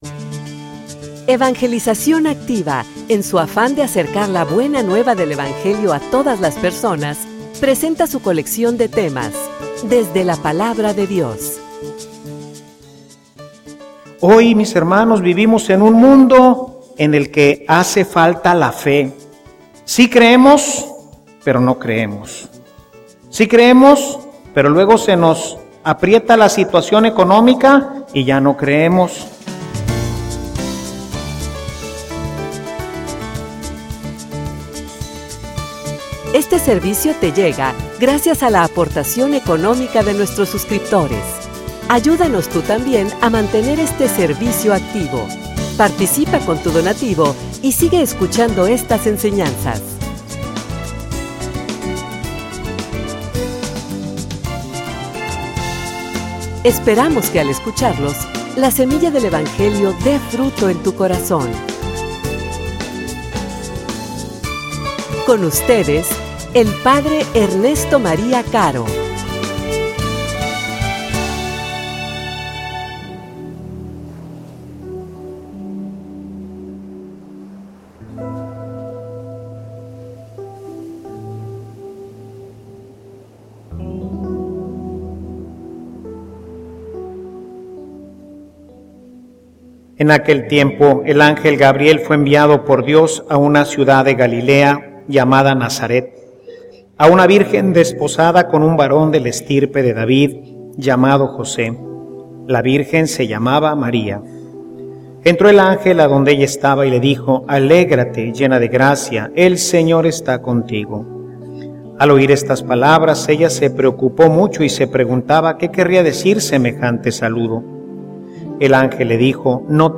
homilia_Preparando_la_navidad_final.mp3